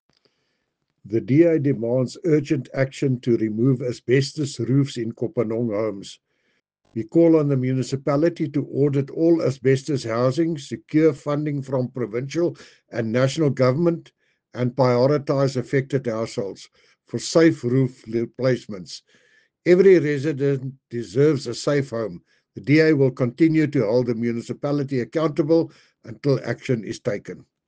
Afrikaans soundbites by Cllr Jacques van Rensburg and